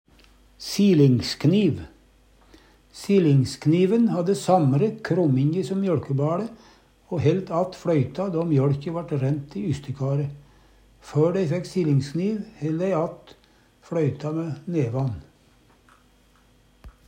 DIALEKTORD